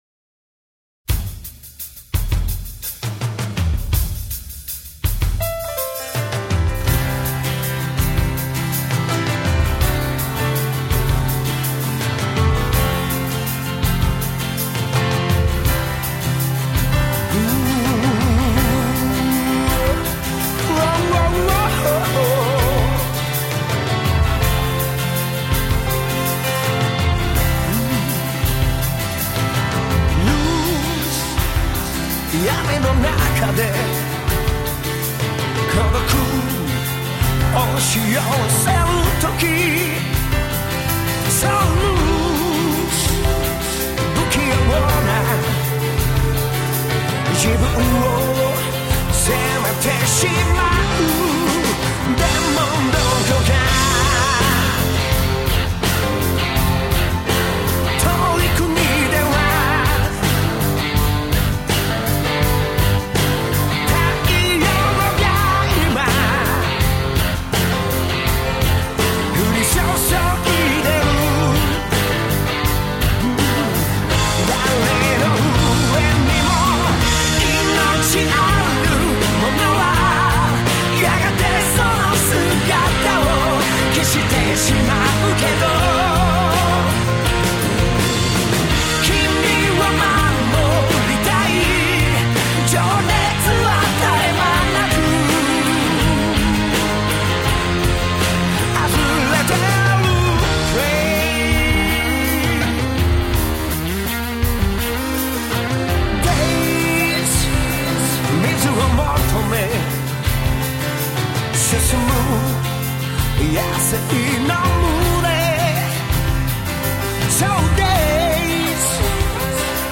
(오케스트라와 기계음의 현란한 조화를 보여준다.)